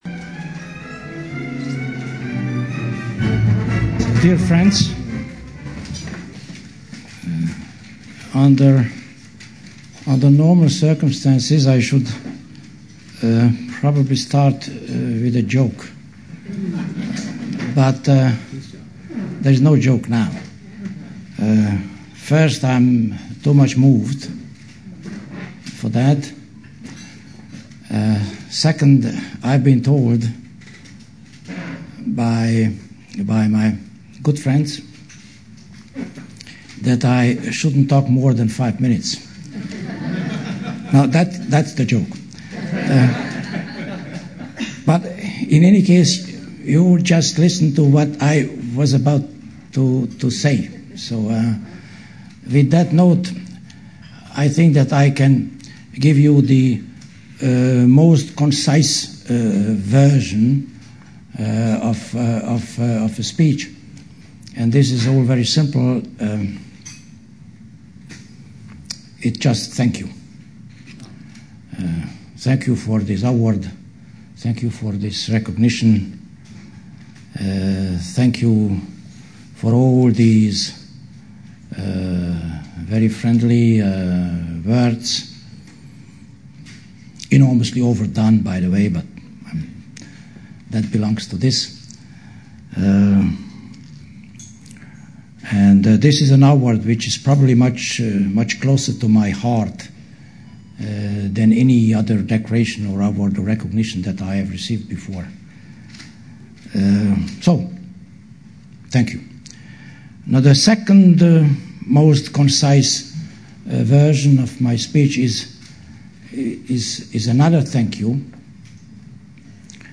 In accepting the award, Dr. Martonyi said that among the many recognitions he has received in his career as civil servant, this award was the most meaningful, because it comes from those who share his commitment to ensure that all Hungarians dispersed throughout the world are part of a free and sovereign Hungarian nation.